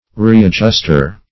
Readjuster \Re`ad*just"er\ (r[=e]`[a^]d*j[u^]st"[~e]r), n.